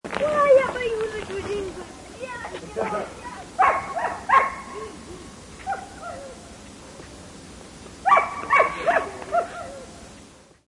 Dog Barking Lo Fi Cassette Russia 920526 Efecto de Sonido Descargar